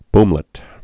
(bmlĭt)